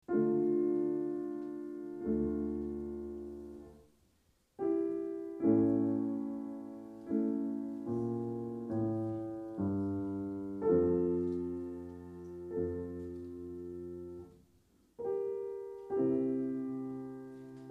Sarabande